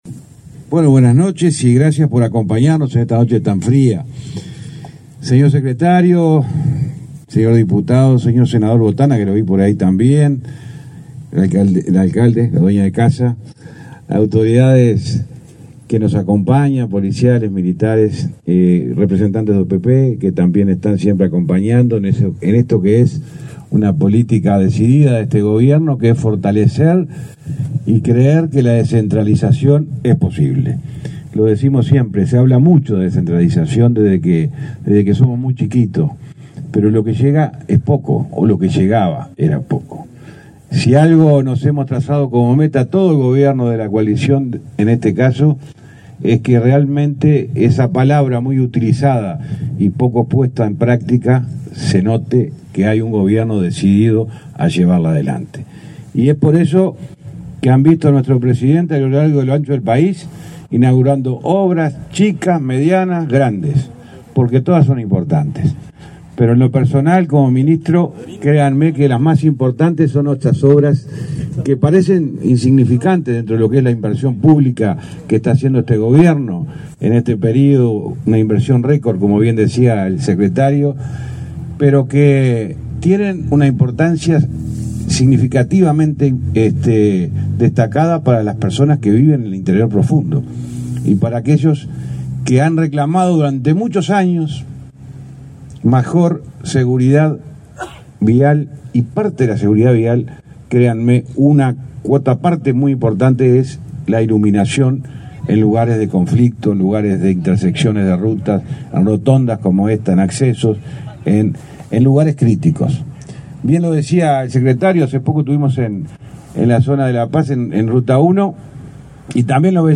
Palabras del ministro de Transporte, José Luis Falero
Palabras del ministro de Transporte, José Luis Falero 18/04/2023 Compartir Facebook X Copiar enlace WhatsApp LinkedIn El Ministerio de Transporte y Obras Públicas (MTOP) inauguró, este 14 de abril, luminarias sobre ruta n.° 21 y el bypass de acceso a la localidad de Carmelo, en el departamento de Colonia. Participó en el evento el titular de la cartera, José Luis Falero.